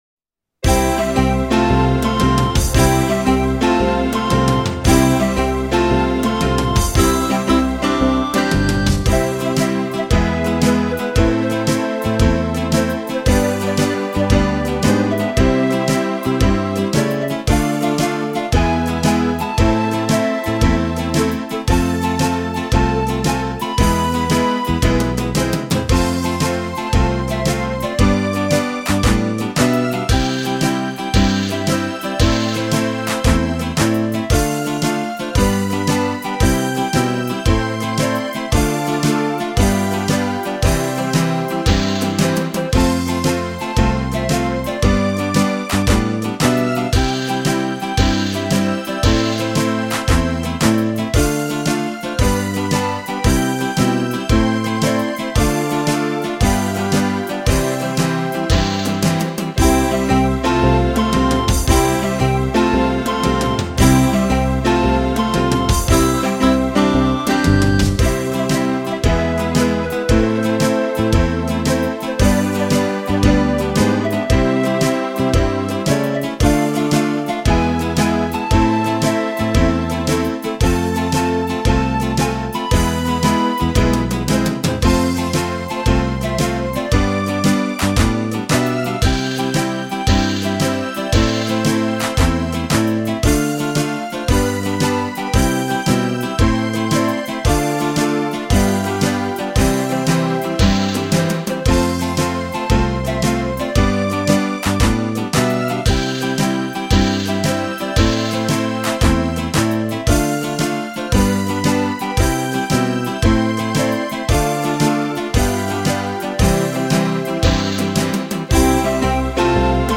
Gytariu_himnas(instrument).mp3